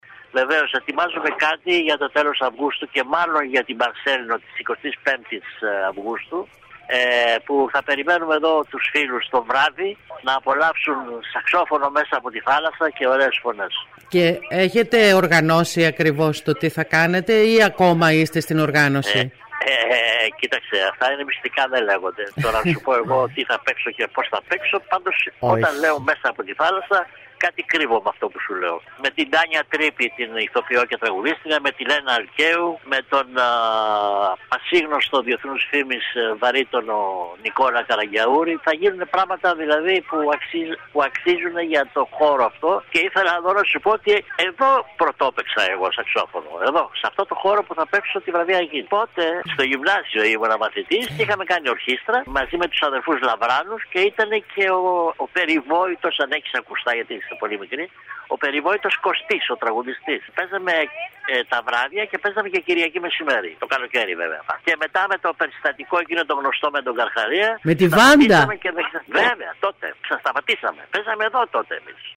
Ακούμε τον συνθέτη Γιώργο Κατσαρό.